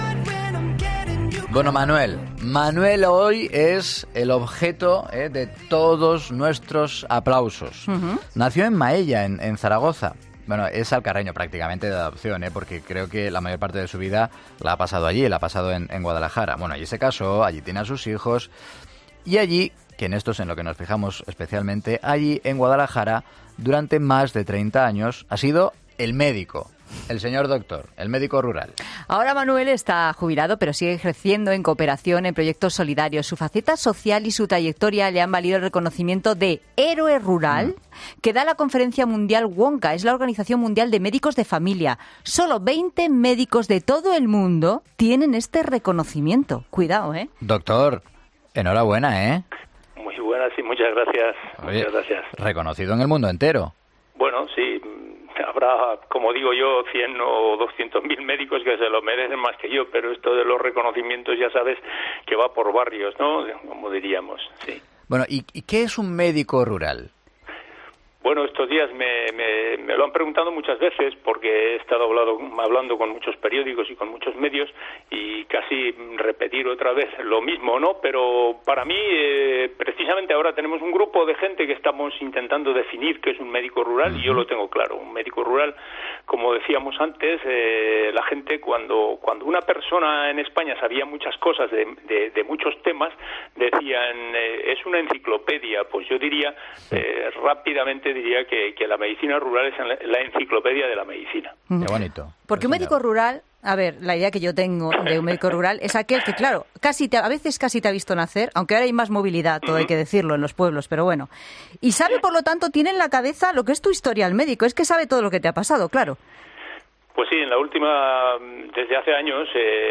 Entrevistas en Mediodía COPE